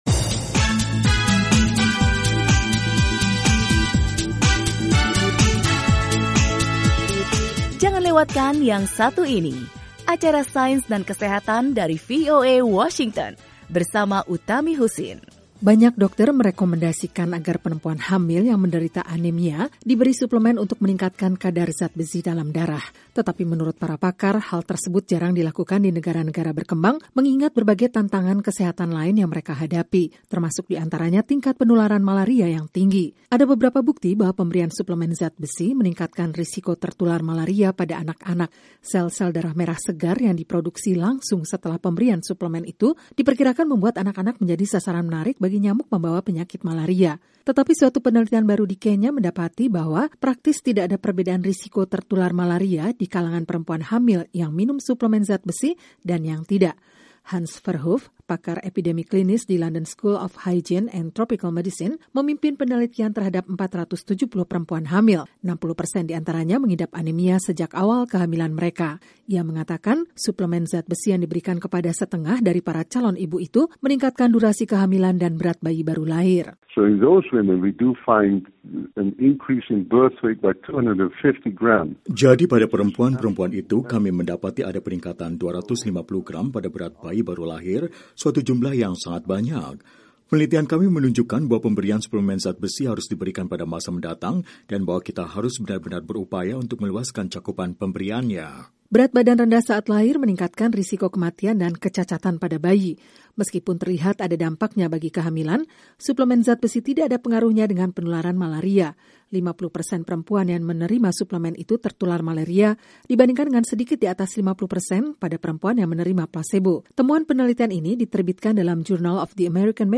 Suplemen zat besi tidak meningkatkan risiko perempuan hamil tertular malaria di daerah-daerah endemik, tetapi justru bermanfaat bagi mereka. Laporan